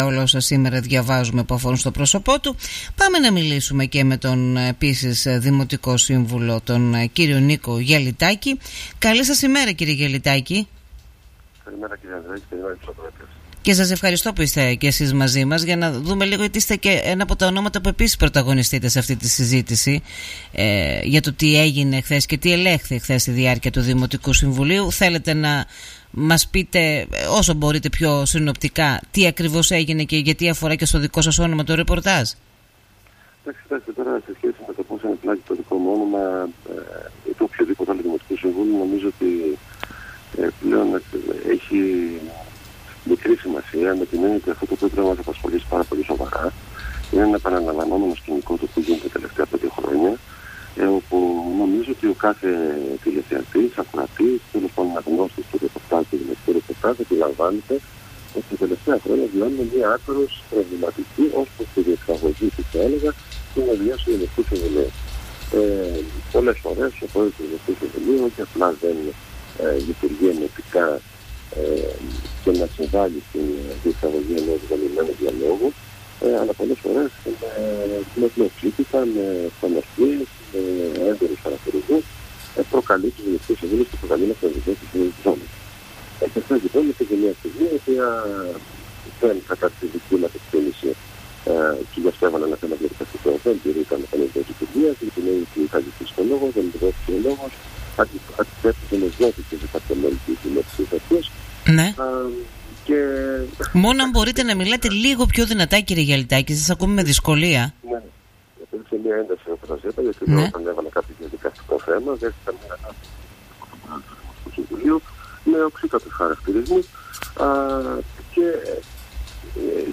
Ακούστε εδώ ολόκληρη την συνέντευξη του Δημοτικού Συμβούλου με την παράταξη Ηράκλειο 3.7.5 Νίκου Γιαλιτάκη στον Politica 89.8: